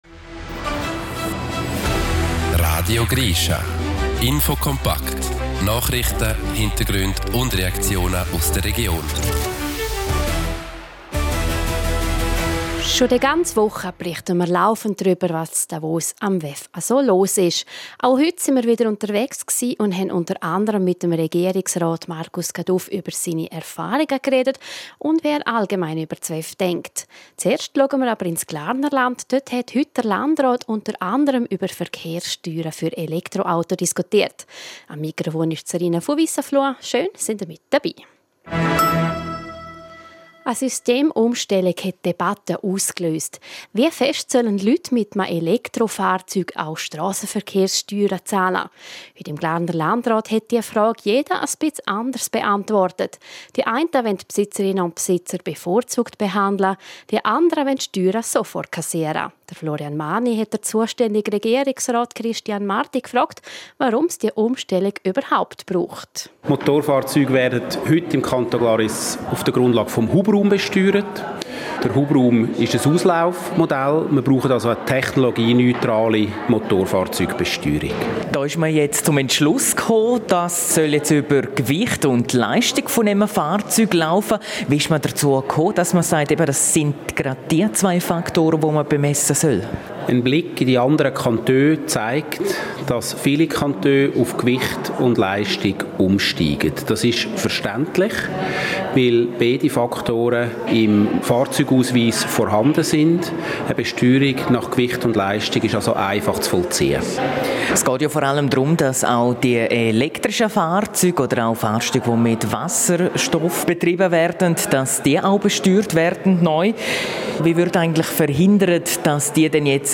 Regierungsrat Marcus Caduff erzählt uns im Interview, wie er über das WEF denkt und seine Erfahrungen damit.